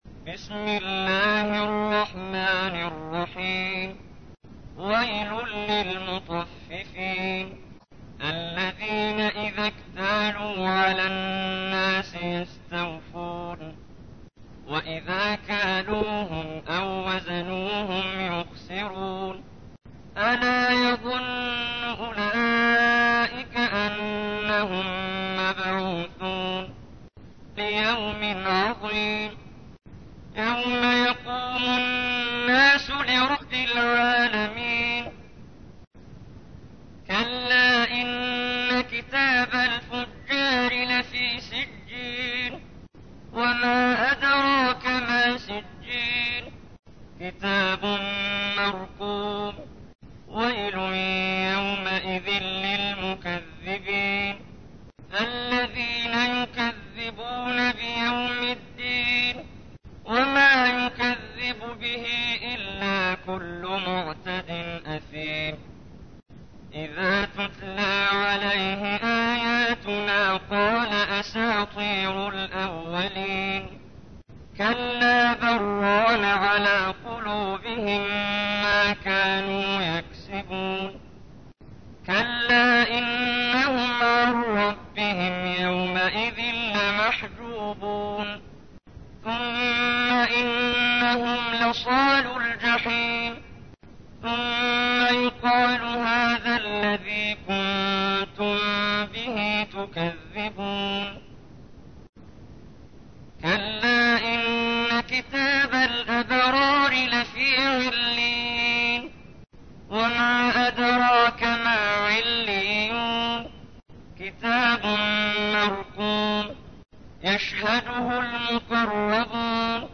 تحميل : 83. سورة المطففين / القارئ محمد جبريل / القرآن الكريم / موقع يا حسين